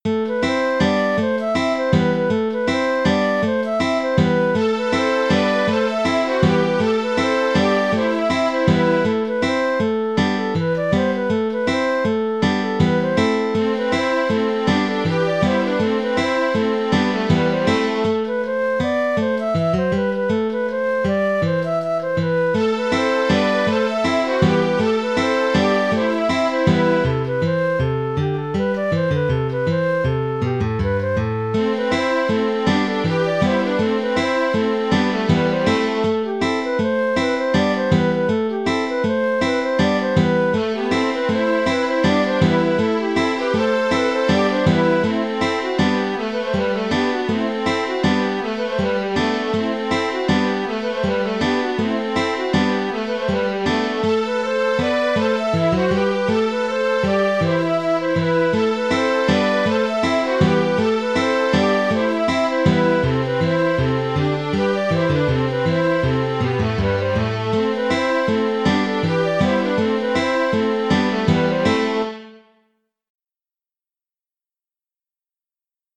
Ridée 6 temps) - Musique bretonne
Auteur : Trad. Bretagne.